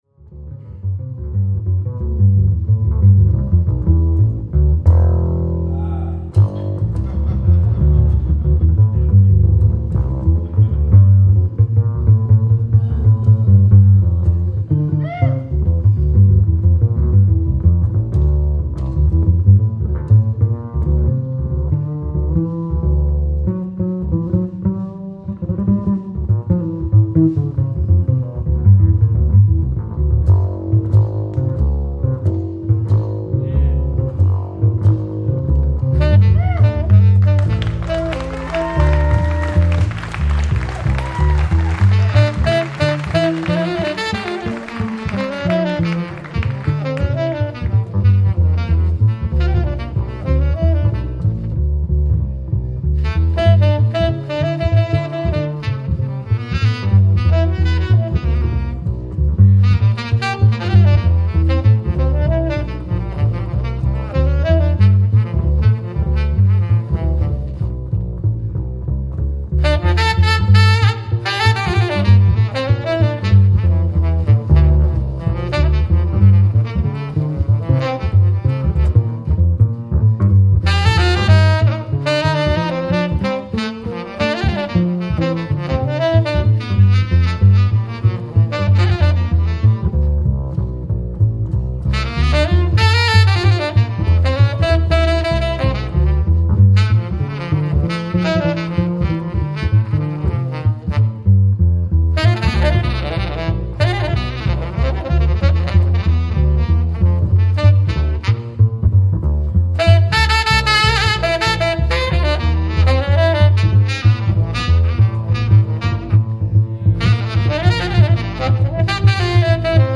Recorded live at the Vision Festival, NYC